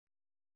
♪ ṛoṭṭu